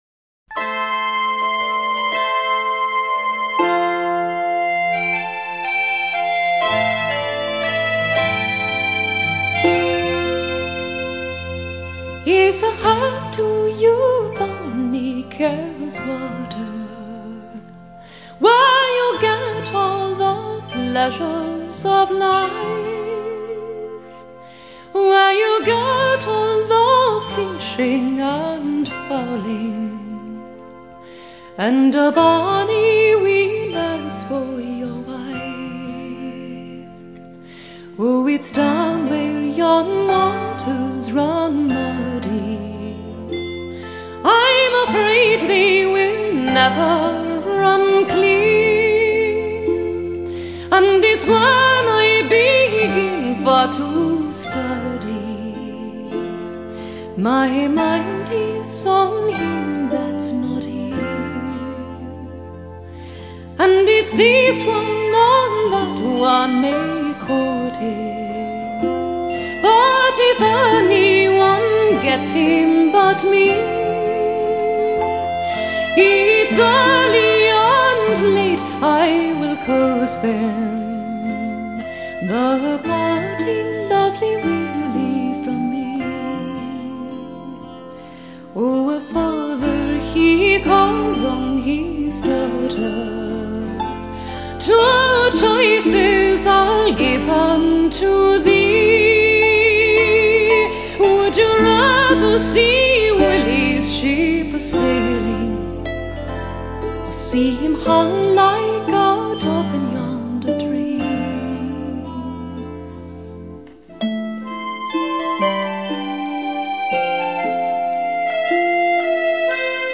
音乐类别：爵士人声
一切的一切，目的就是为了表现凯尔特音乐特有的旋律和味道。
音乐的旋律固然极其古朴而悠扬，但是歌词听来都具有爱尔兰特有的韵味。